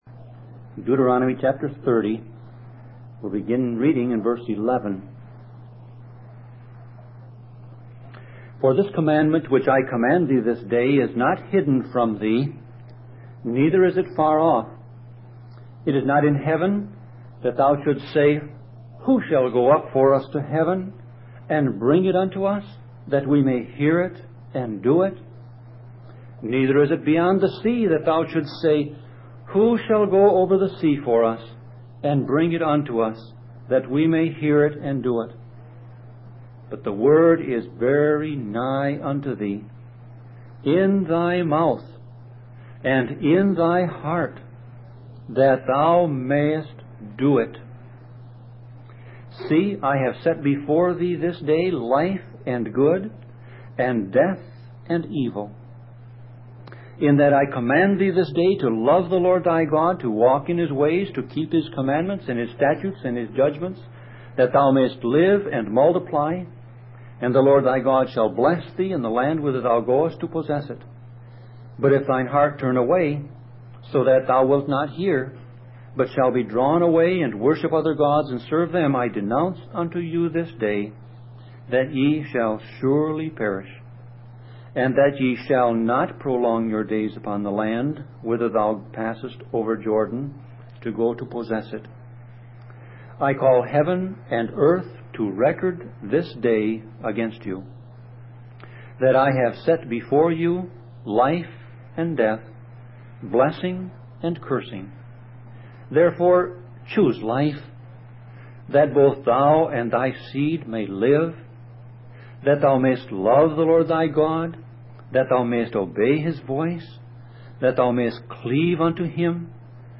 Sermon Audio Passage: Deuteronomy 30:11-20 Service Type